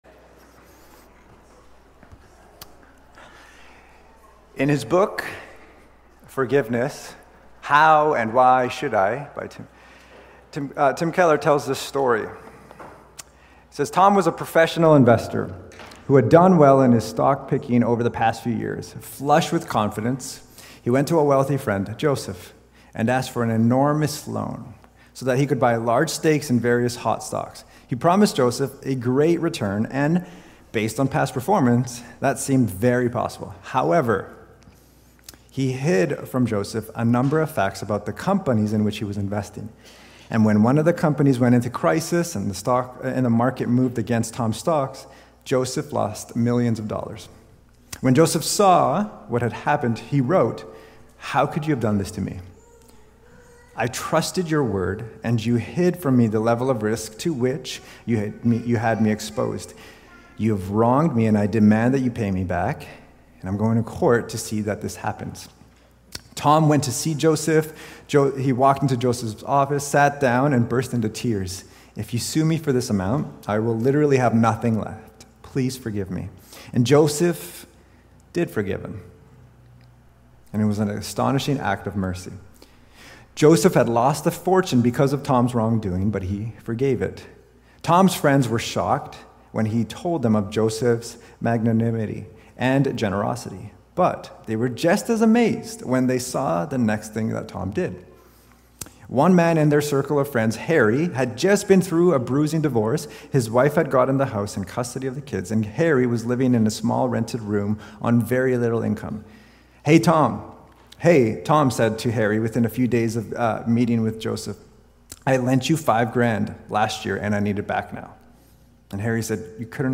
Cascades Church Sermons